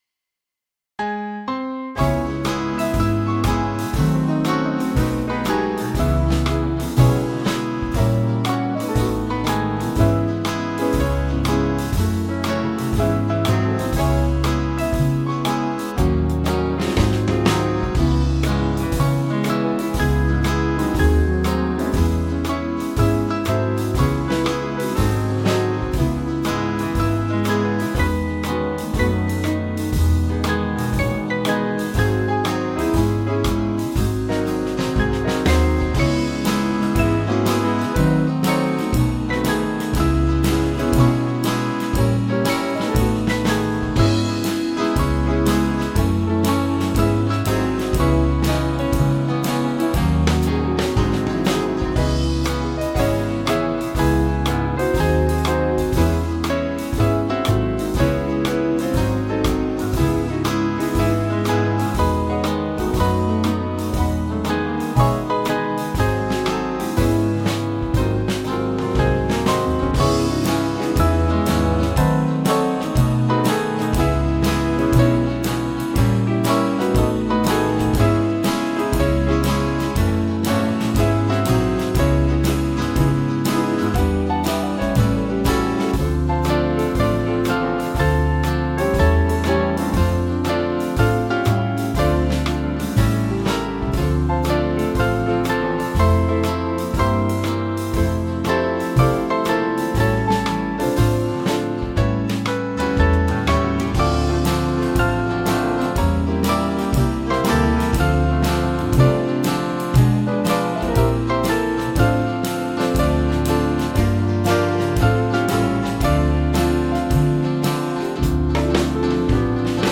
Swing Band
(CM)   3/Dbm-Dm 478.9kb